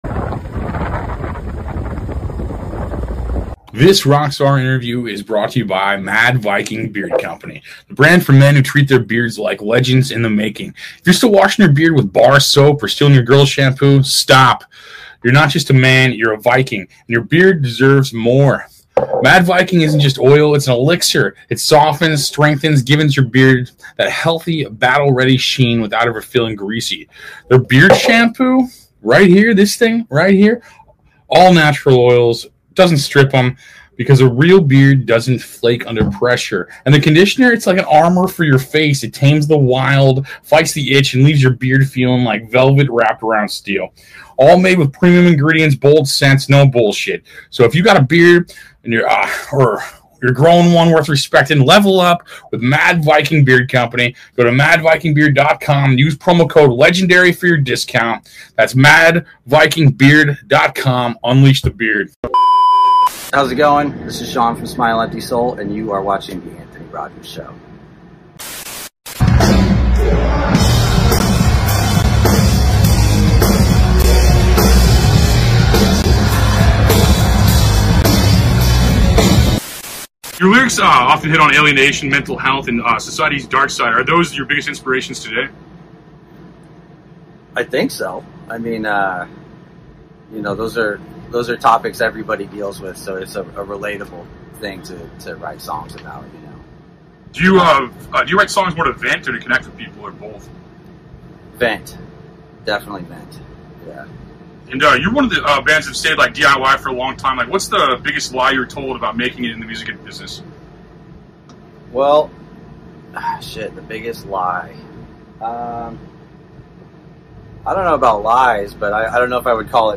SMILE EMPTY SOUL interview